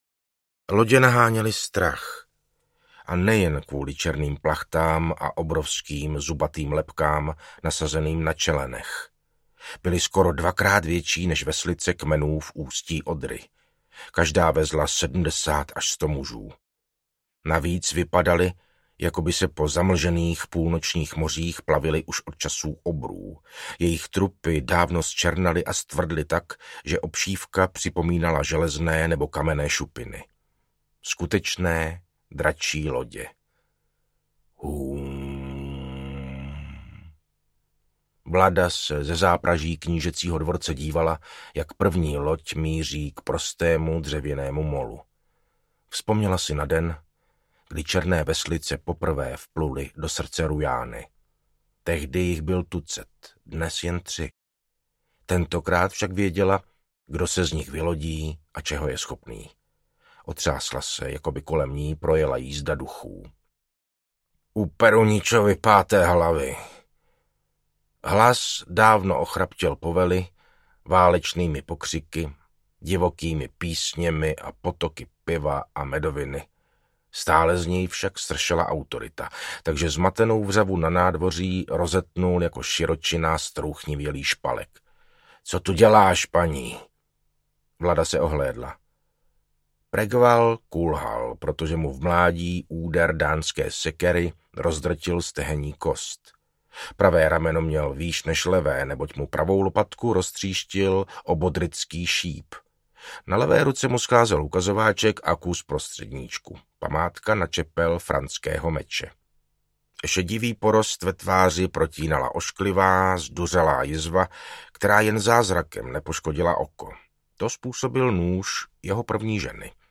Ukázka z knihy
• InterpretMartin Finger